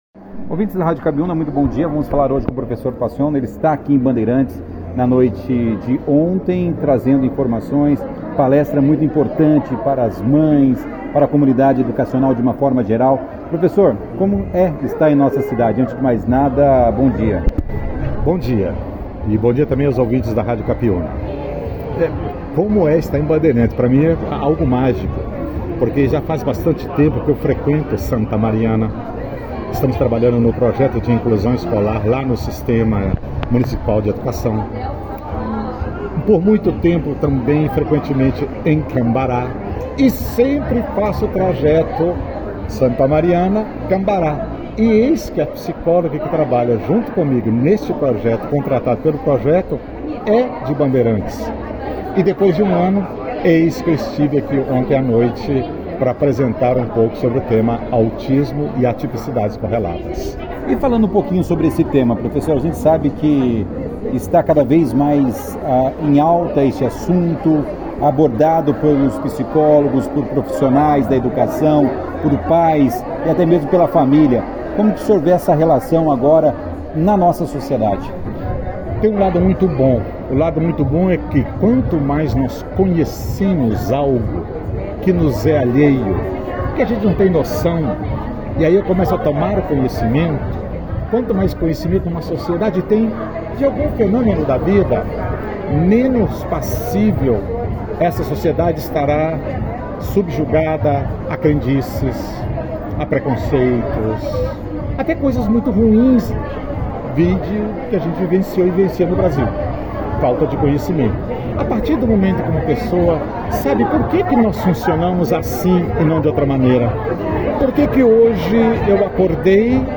A palestra foi destaque na 2ªedição do jornal “Operação Cidade” nesta quarta-feira, 18 de outubro.